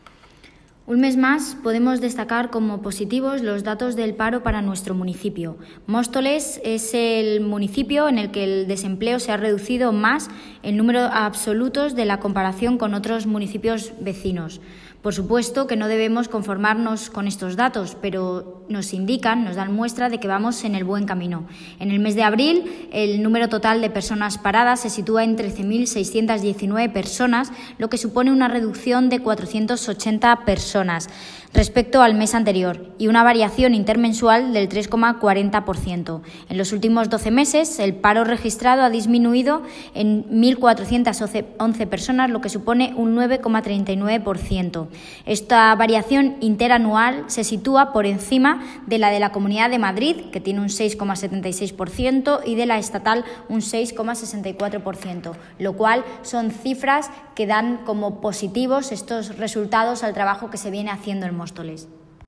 Audio - Jessica Antolín (Concejala Desarrollo Económico, Empleo y Nuevas Tecnologías) Sobre Datos Paro